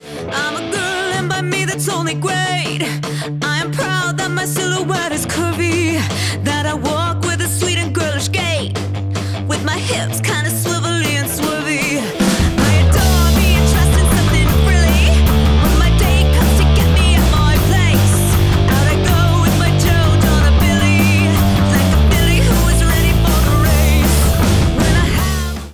Pop/Punk